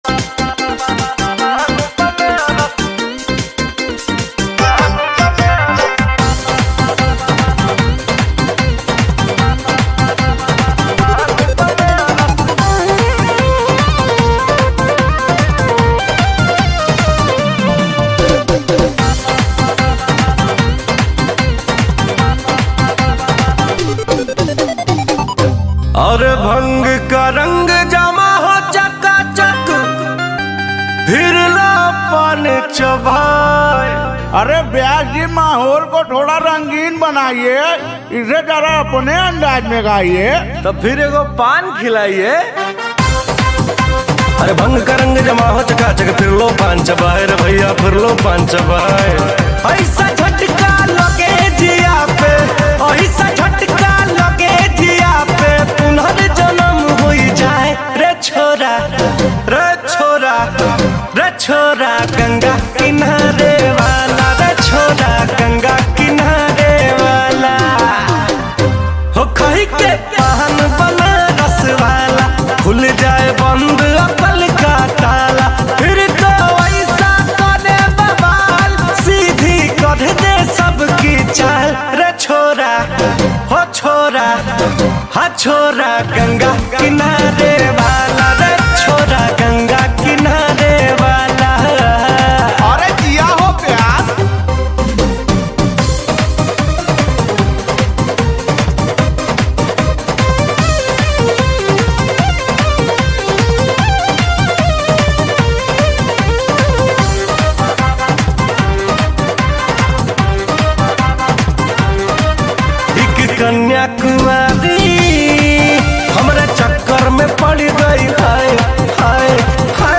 Bhojpuri Songs